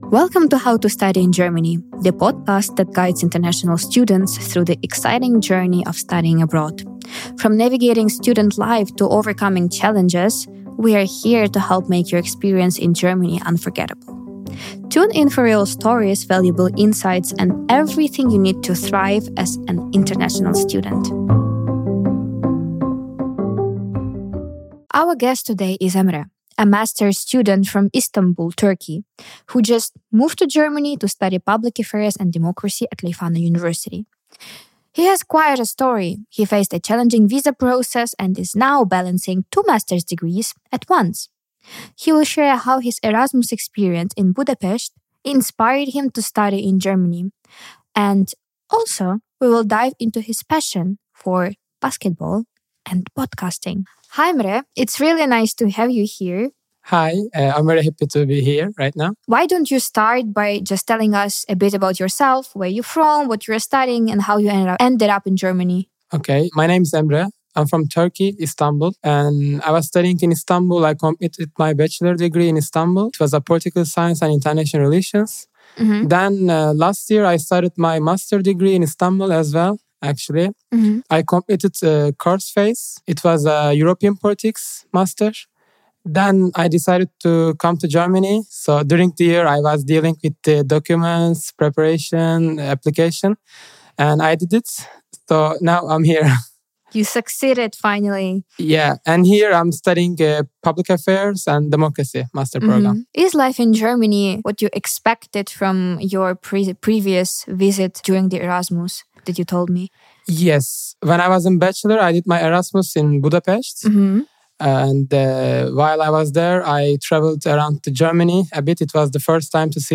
Tune in for an inspiring and honest conversation about starting fresh in a new country!